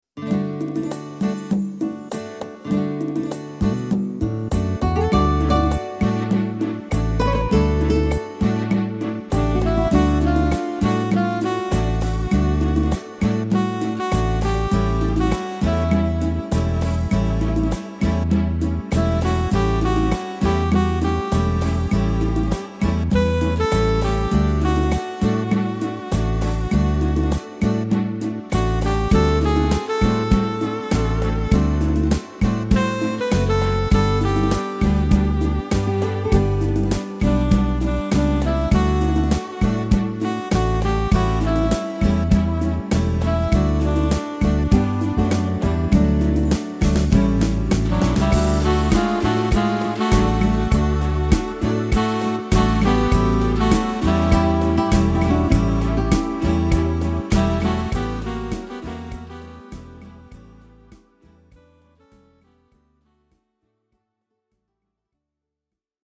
Tempo: 100 / Tonart: D / Eb / E / Eb – Dur